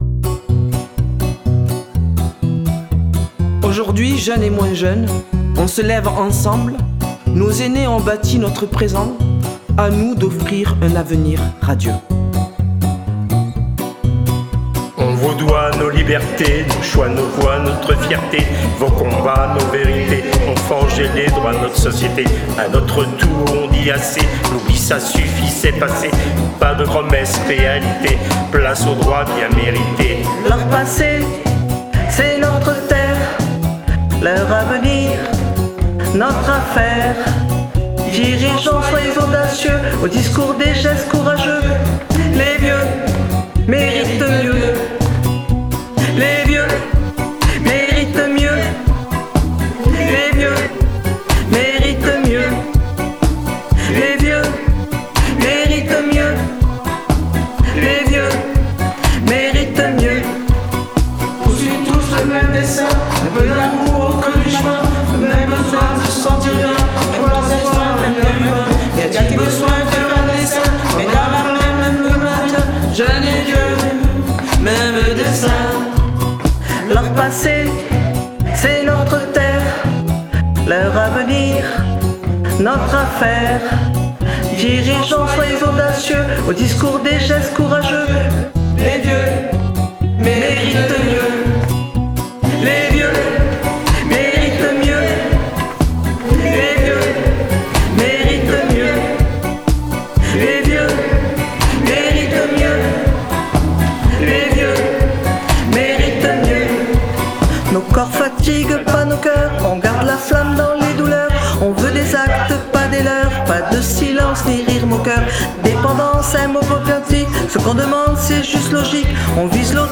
Retour en chanson
Chanson créée par les résidents de la Maison protestante de Montpellier